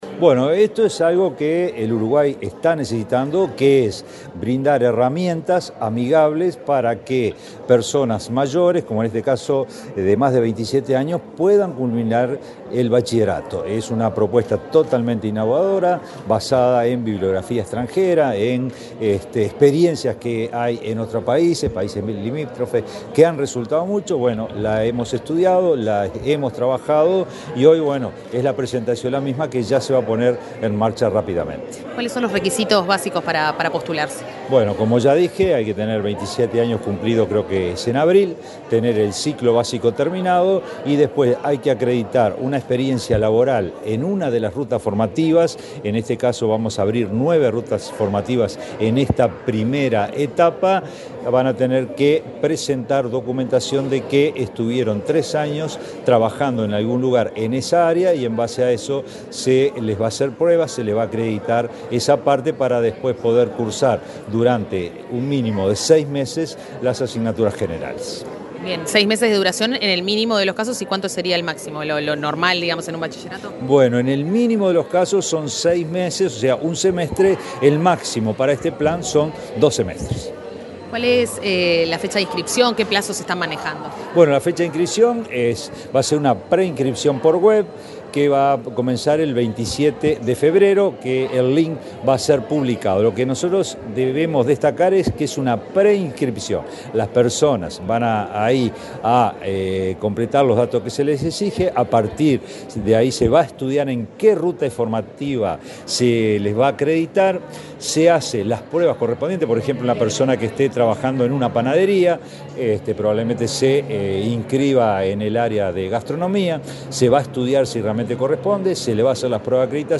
Entrevista al director general de UTU, Juan Pereyra